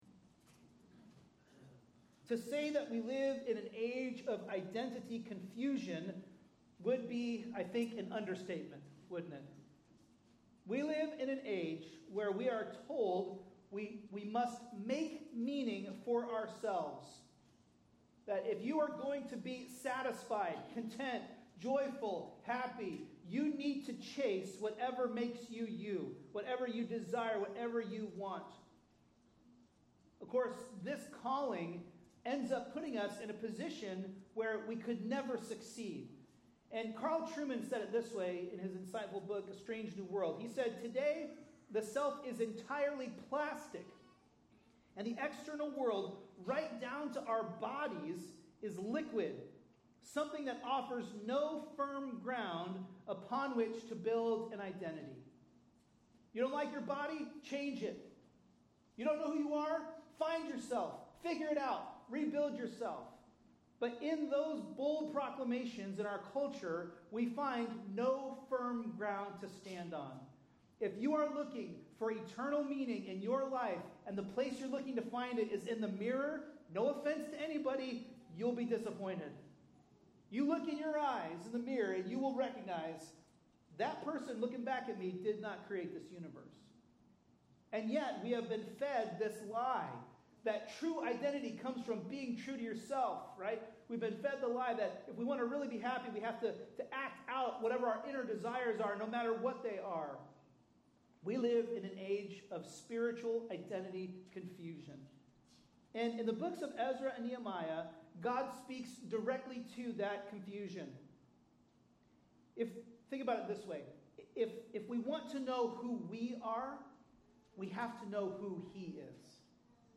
A message from the series "Ezra/Nehemiah." In Nehemiah 1:1-11, we learn that God's restoration is complete restoration.